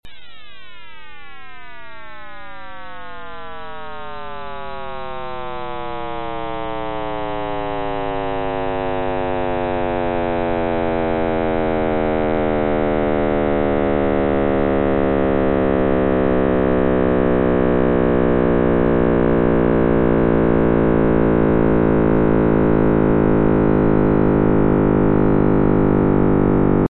The audio file simulates pressure waves in the dense plasma of the early universe, which were caused by fluctuations in temperature and density. These fluctuations are what eventually led to the formation of galaxies and other cosmic structures.